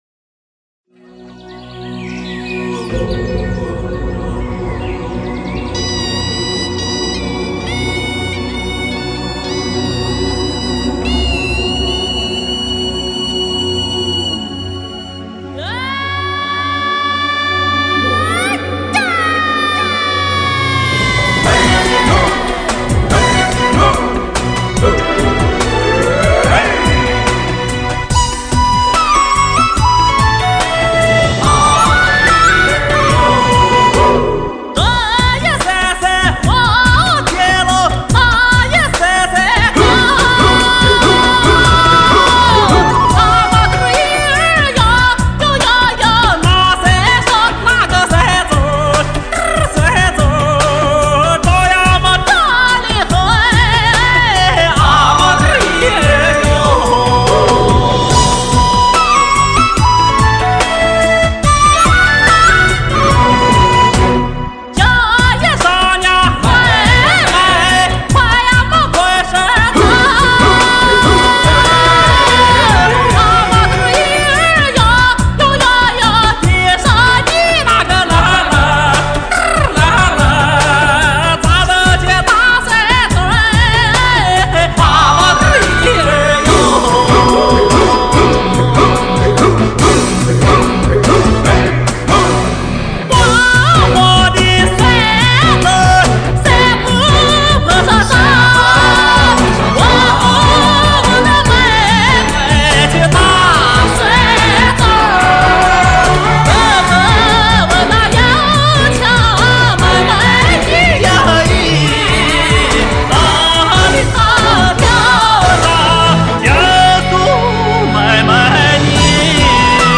因为是现场录音，音质比较一般